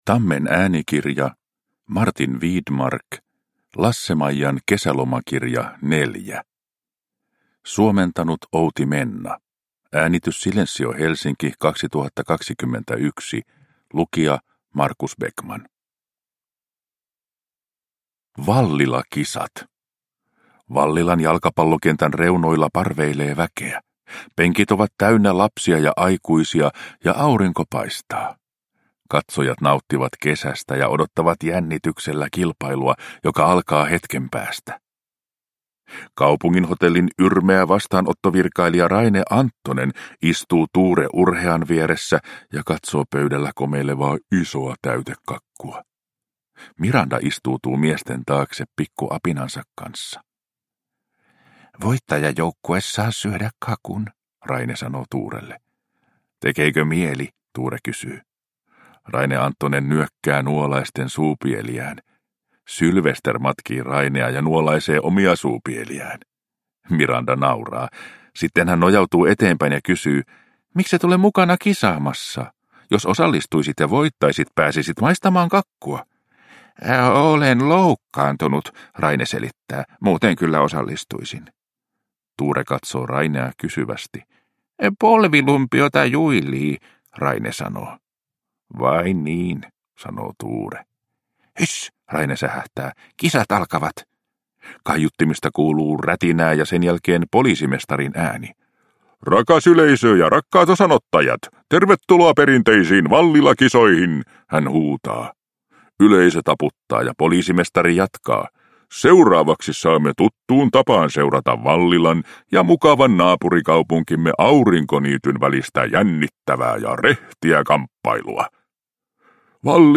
Lasse-Maijan kesälomakirja 4 – Ljudbok – Laddas ner